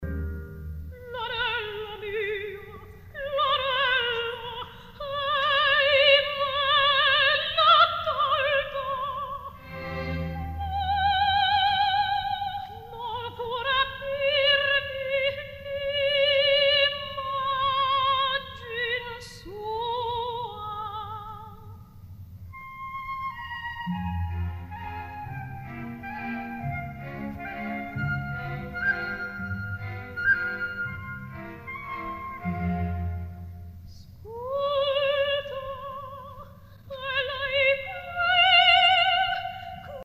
digitally restored